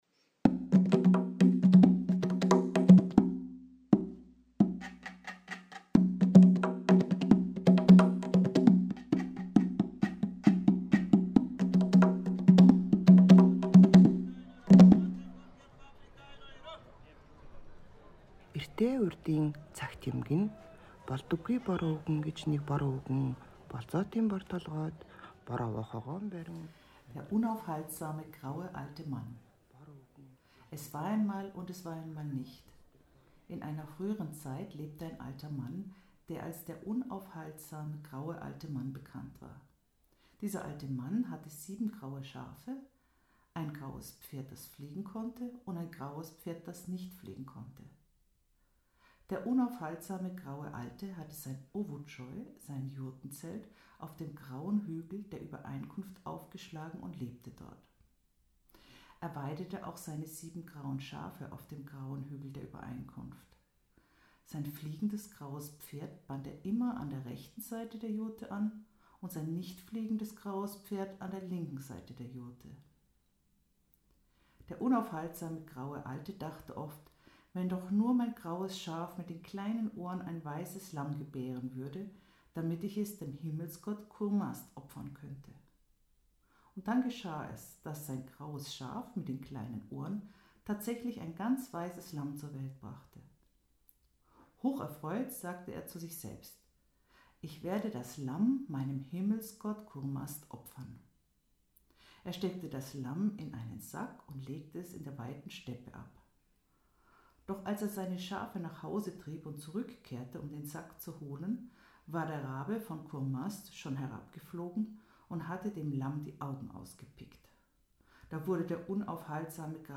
Es war einmal, es war einmal nicht ist eine Online-Ausstellung und Audio-Erfahrung, die als wachsende Sammlung von Volksmärchen konzipiert ist, die von Frauen mit Migrationshintergrund aus den Ländern entlang der alten Seidenstraße – die sich von Japan bis Italien erstreckt – erzählt und durch zeitgenössische Kunstfotografie illustriert werden.